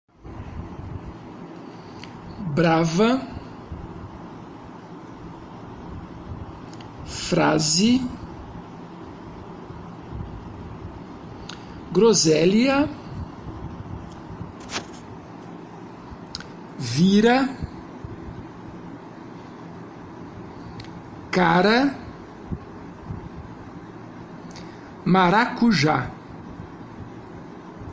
Faça download dos arquivos de áudio e ouça a pronúncia das palavras a seguir para transcrevê-las foneticamente.
GRUPO 4 - Tepe - Arquivo de áudio -->